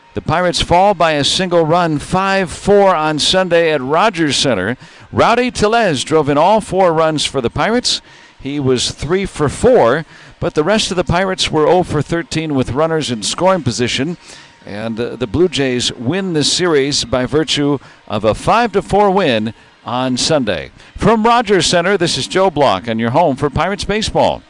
recap.